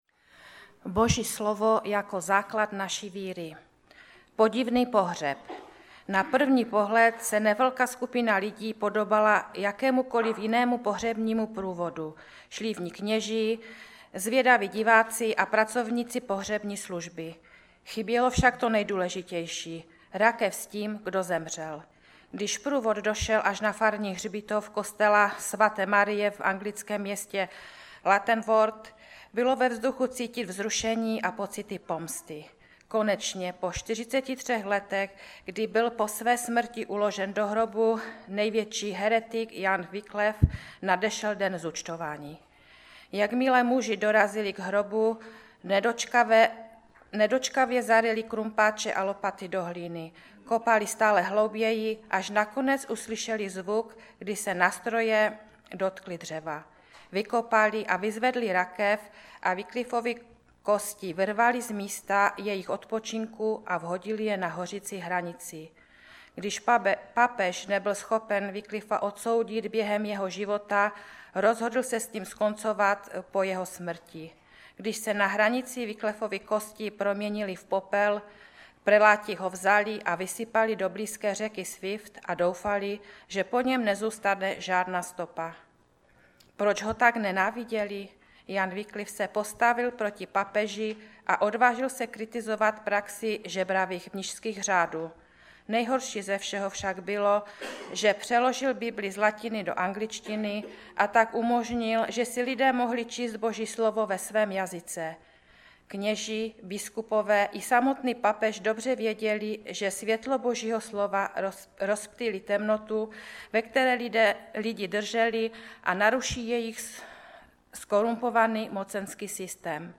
Sbor Ostrava-Radvanice. Shrnutí přednášky začíná po 12-té minutě.